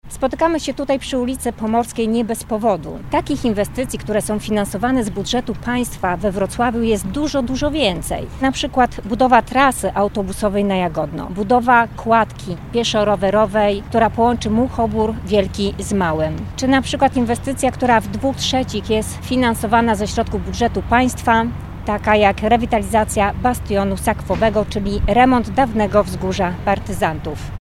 Pełnomocnik struktur PiS w okręgu wrocławskim przedstawiła listę inwestycji, finansowanych we Wrocławiu przez rząd. Podczas konferencji skierowano apel do Prezydenta Wrocławia.